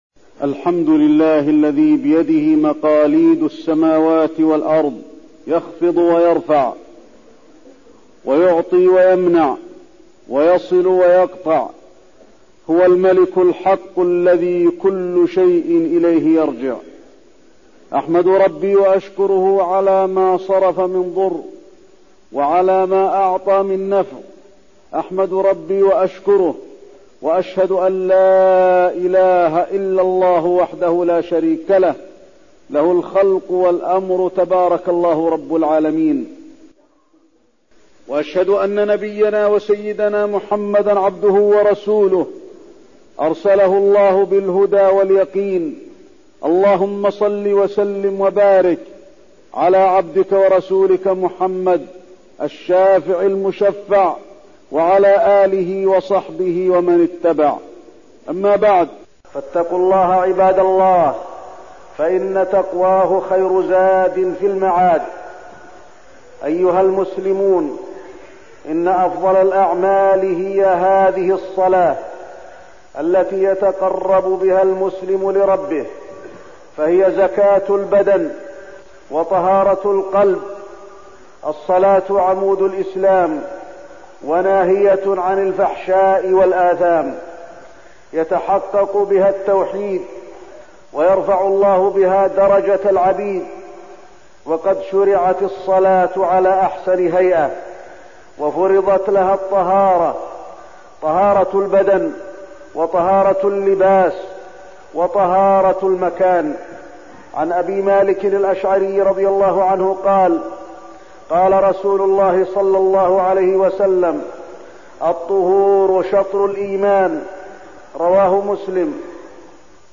تاريخ النشر ١٢ شعبان ١٤١٨ هـ المكان: المسجد النبوي الشيخ: فضيلة الشيخ د. علي بن عبدالرحمن الحذيفي فضيلة الشيخ د. علي بن عبدالرحمن الحذيفي الطهارة والصلاة والمسح على الخفين